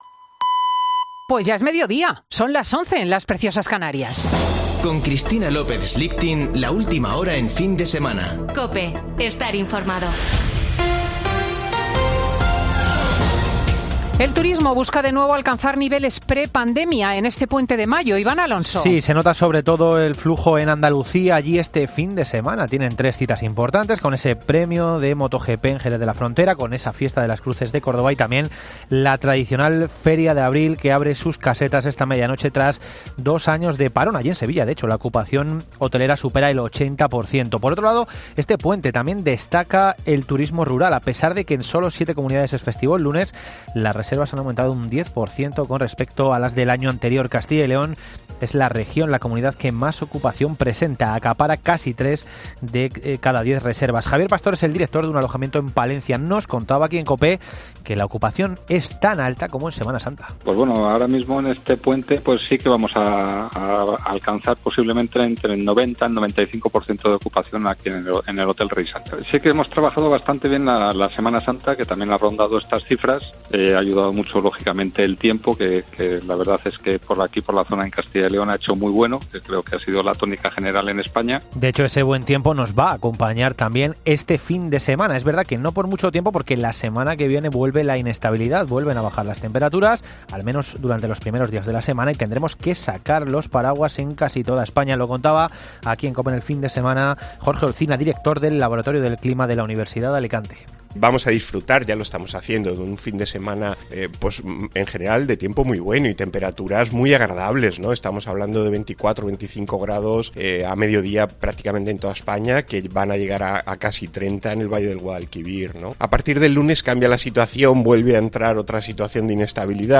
Boletín de noticias de COPE del 30 de abril de 2022 a las 12.00 horas